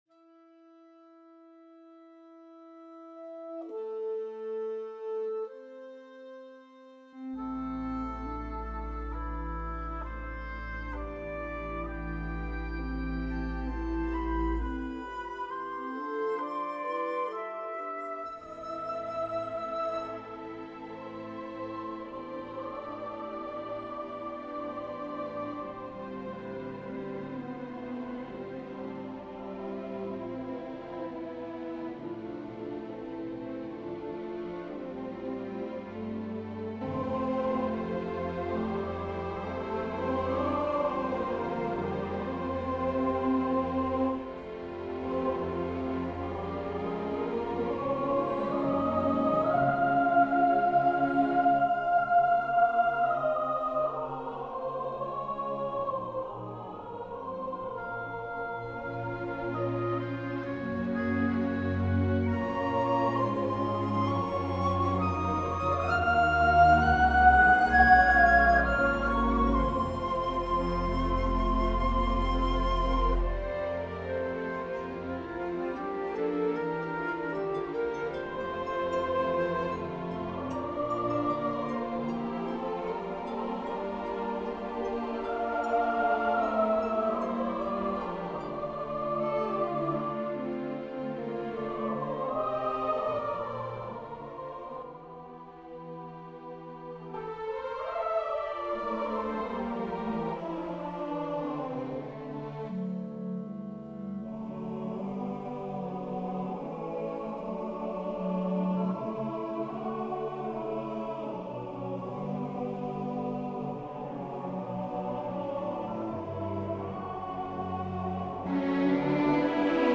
SSAATTBB chorus, piano